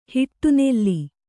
♪ hiṭṭu nelli